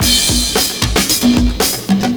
112CYMB02.wav